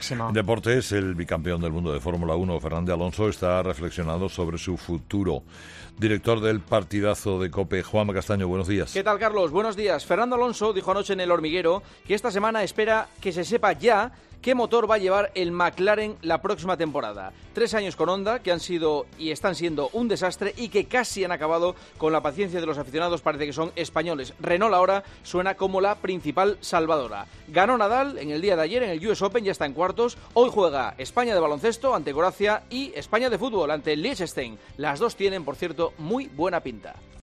AUDIO: El futuro de Fernando Alonso en la Fórmula Uno, en el comentario de Juanma Castaño en 'Herrera en COPE'.